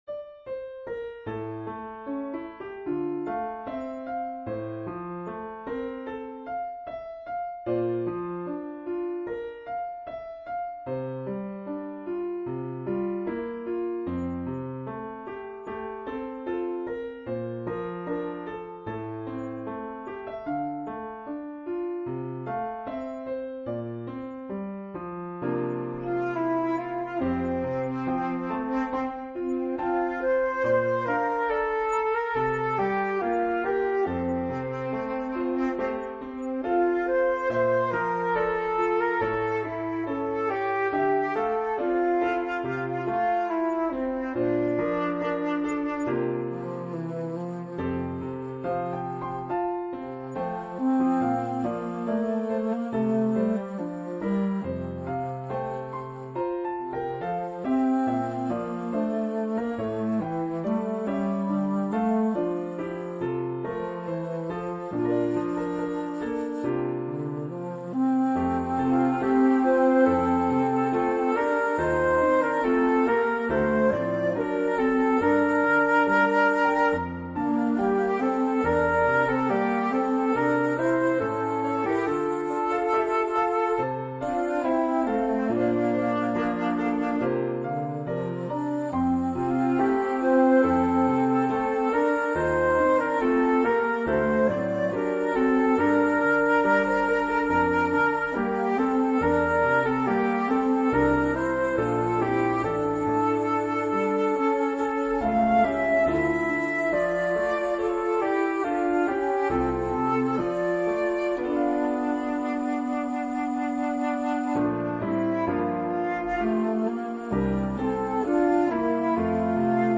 Duet
Voicing/Instrumentation: Duet We also have other 21 arrangements of " Because I Have Been Given Much ".